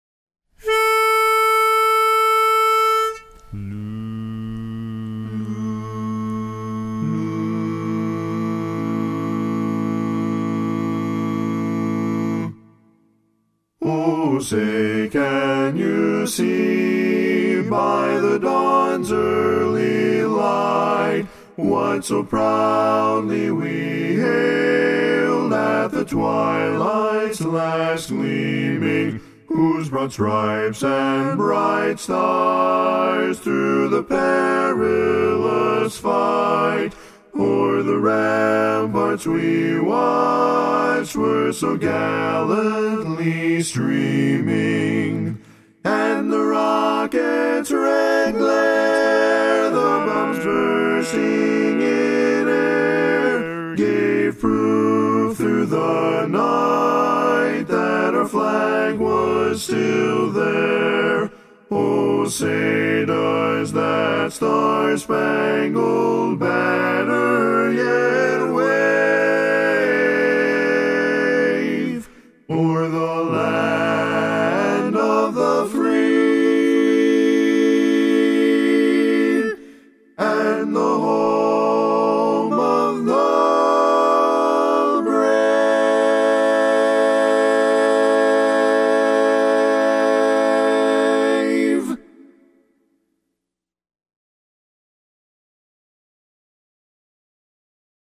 Up-tempo
Barbershop
A Major
Bari